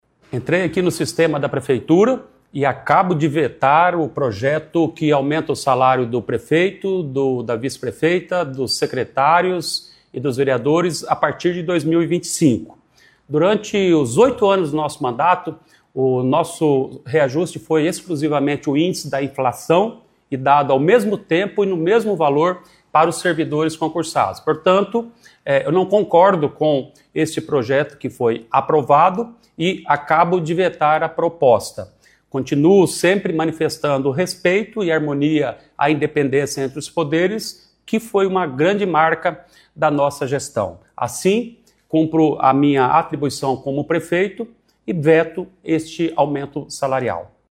Ouça o que disse o prefeito nas redes sociais: